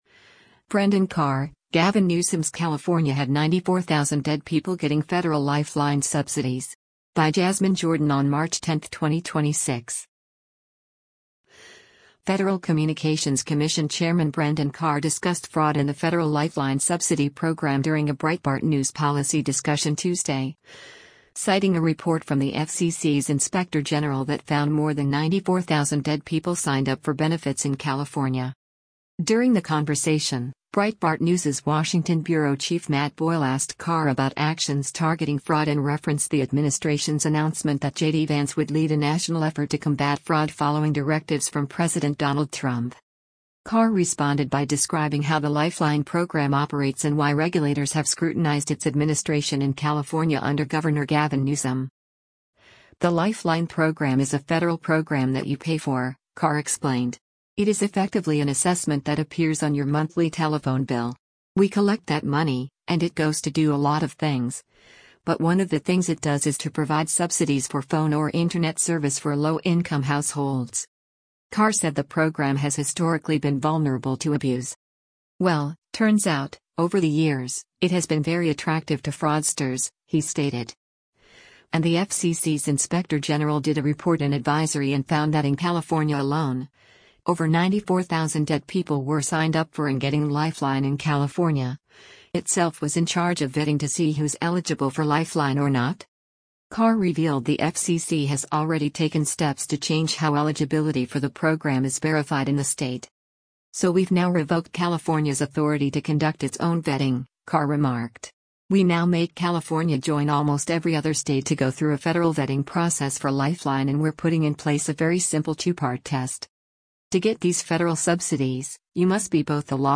Federal Communications Commission Chairman Brendan Carr discussed fraud in the federal Lifeline subsidy program during a Breitbart News policy discussion Tuesday, citing a report from the FCC’s inspector general that found more than 94,000 dead people signed up for benefits in California.